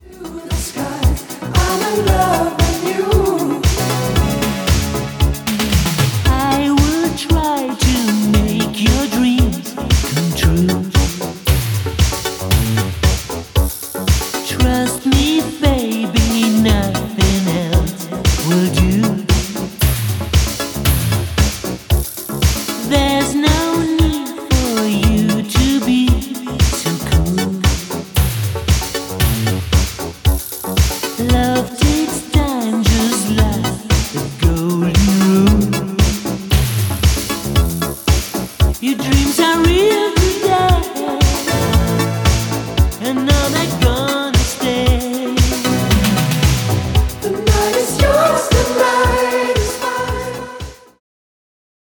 Наткнулся на переделку